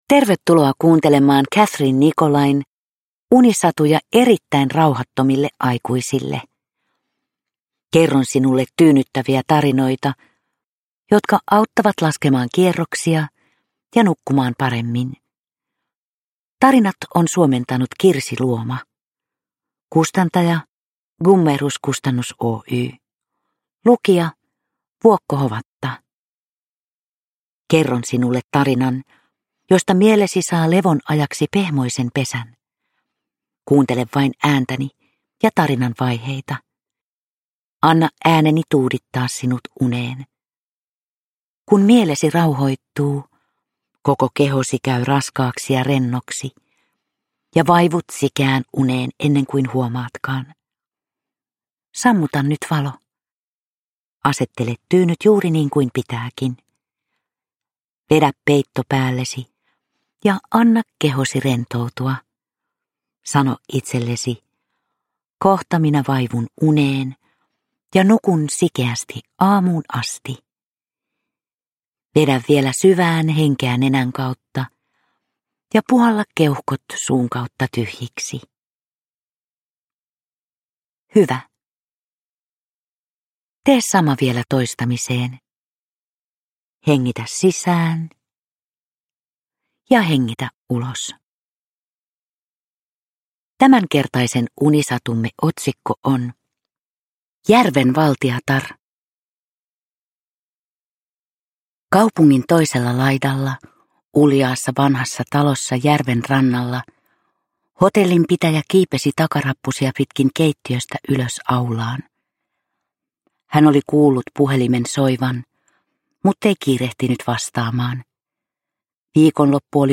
Vuokko Hovatan tyyni ääni saattelee kuulijan lempeästi unten maille.
Uppläsare: Vuokko Hovatta
• Ljudbok